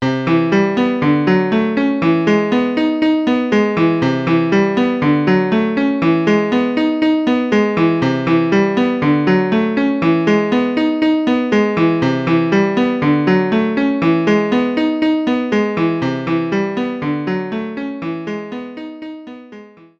Das nächste Beispiel speichert drei Major 7 Akkorde mit den Grundtönen C, D und E.
Zu beachten ist Zeile 11: der zweite Durchlauf des dritten Akkordes geht rückwärts, denn akkord3.reverse liefert eine Kopie des Ringes akkord2 in umgekehrter Reihenfolge.